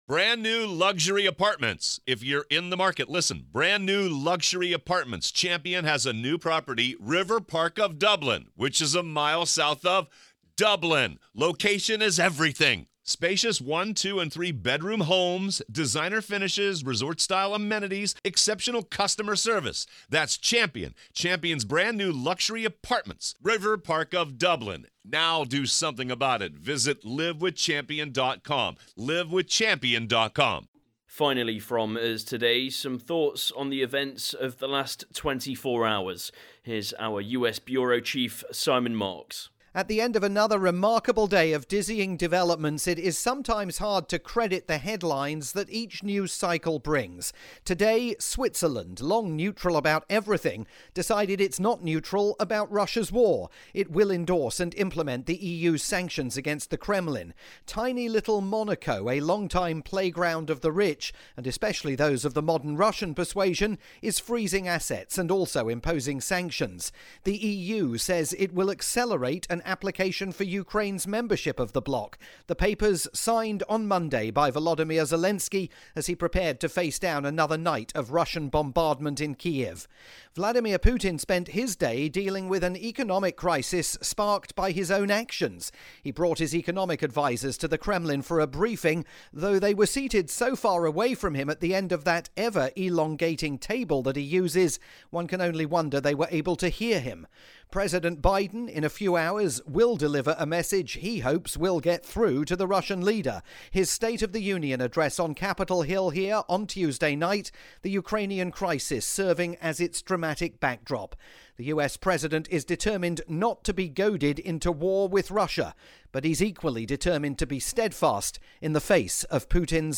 closing essay for CNA 938's "World Report" programme, produced daily for Singapore's top talk radio station.